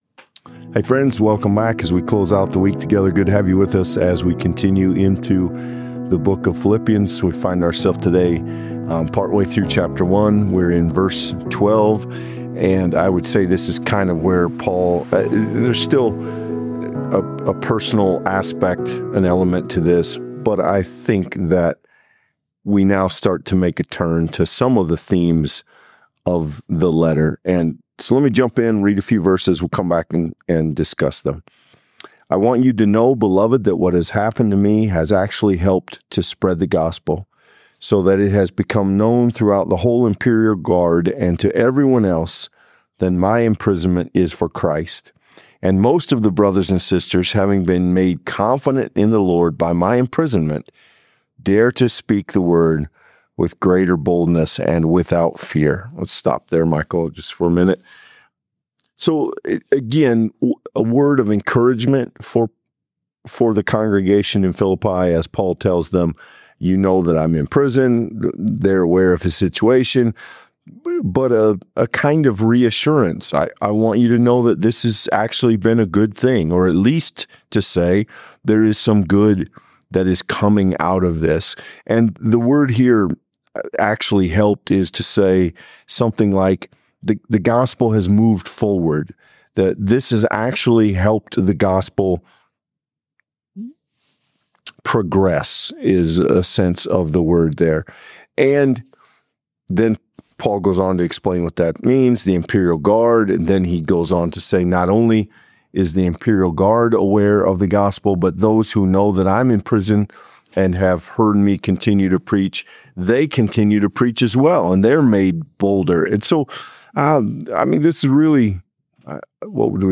This conversation challenges us to consider how the power of the gospel transcends the imperfections of the messenger.